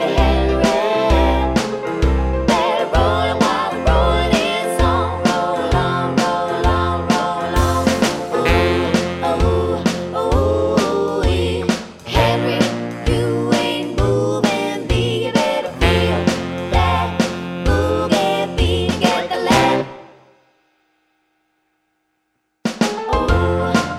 For Duet Without Backing Vocals Jazz / Swing 3:15 Buy £1.50